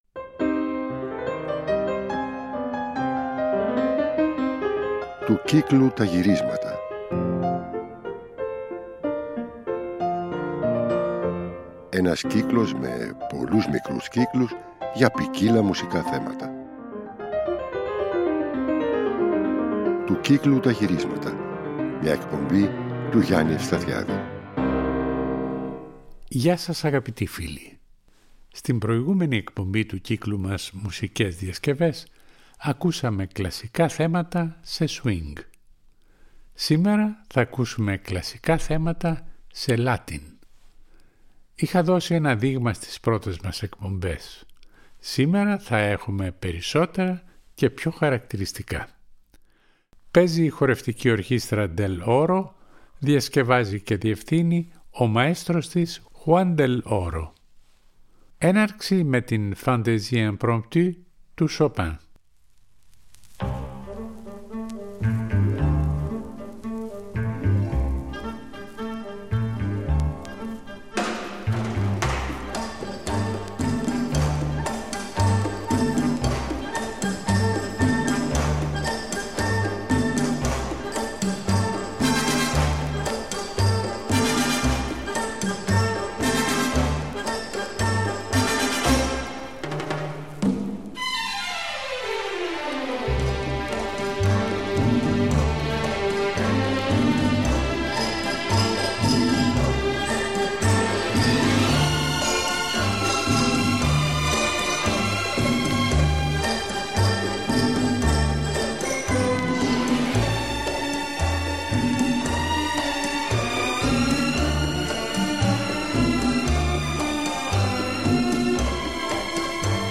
κλαρινέτο, σαξόφωνο και πιάνο
ορχηστρικές διασκευές